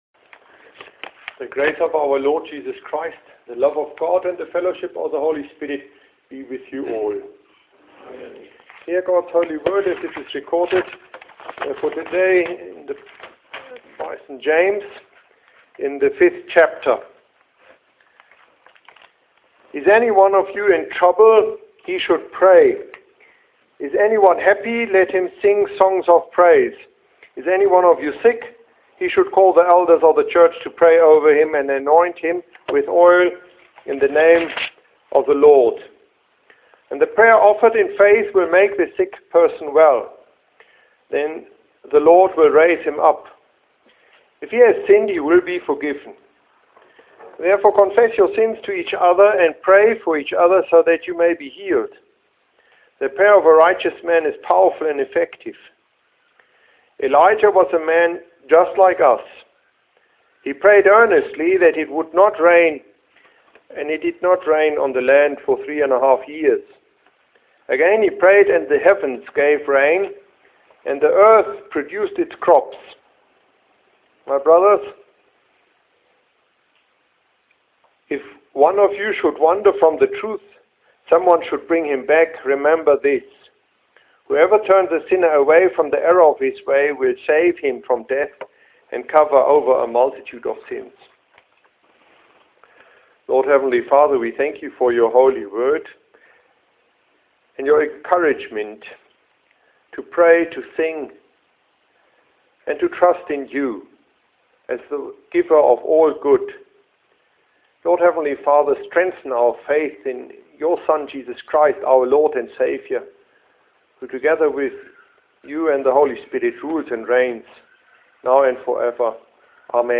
This mornings confessional address on James 5,13-18 can be heard here: